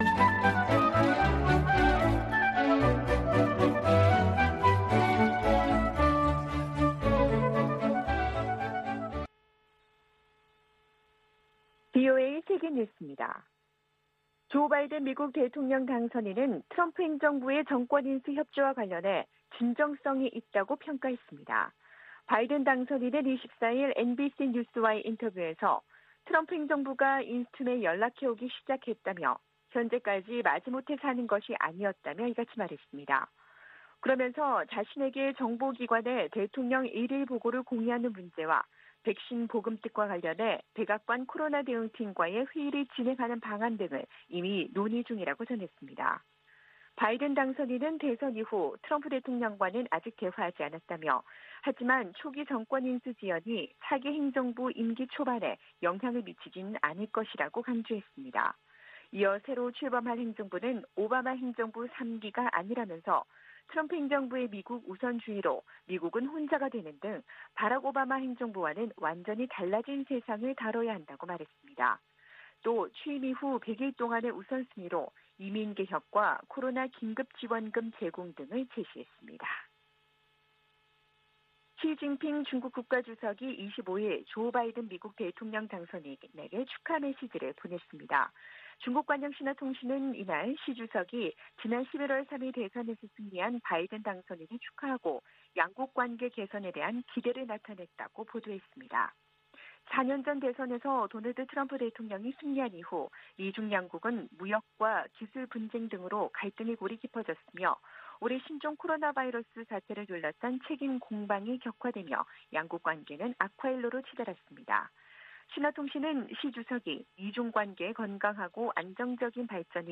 VOA 한국어 아침 뉴스 프로그램 '워싱턴 뉴스 광장'입니다.